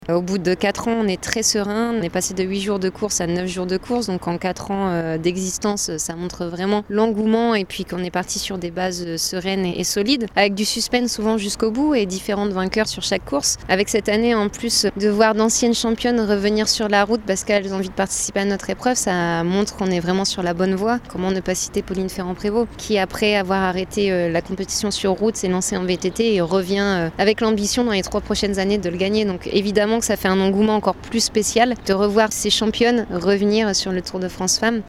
D’année en année, ce tour de France au féminin prend de plus en plus d’ampleur comme le confirme l’ancienne championne Marion Rousse, devenue directrice du tour de France femmes